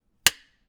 Part_Assembly_28.wav